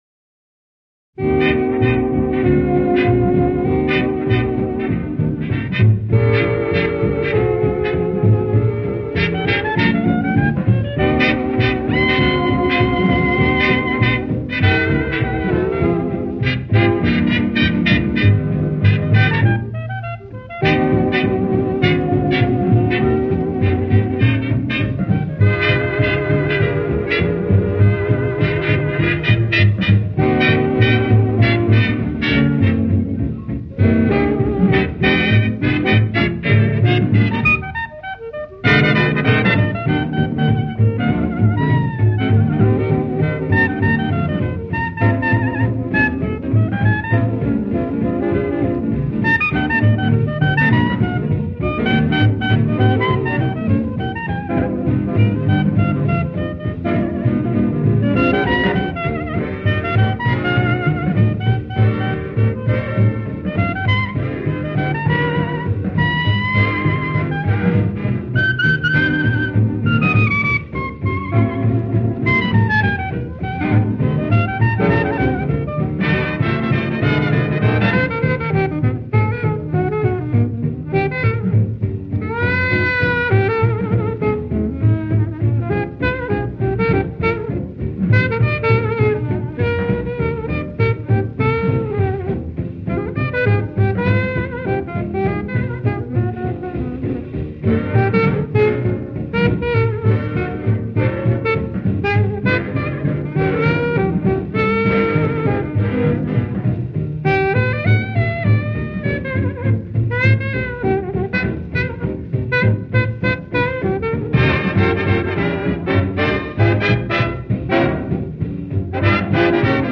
【爵士单簧管】